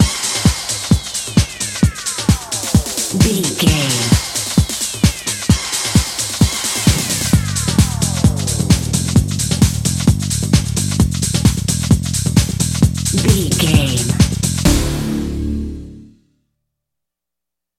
Aeolian/Minor
synthesiser
drum machine
90s